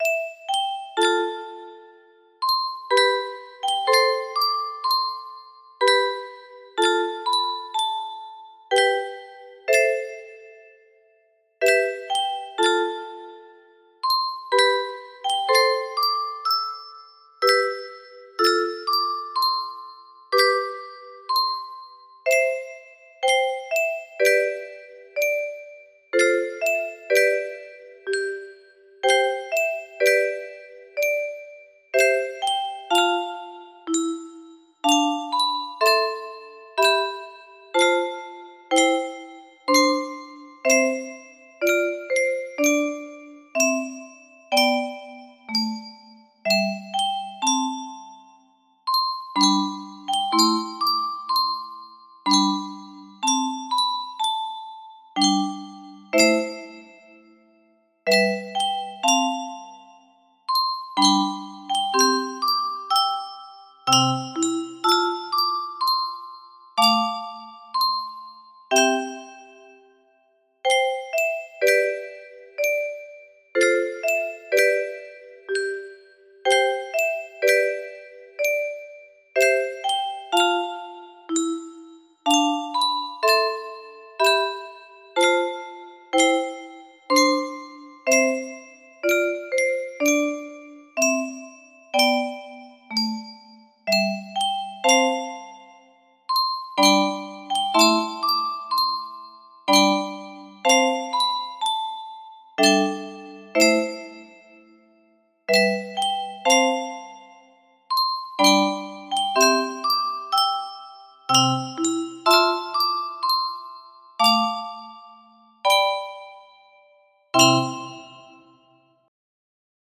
Gustav Holst - Jupiter music box melody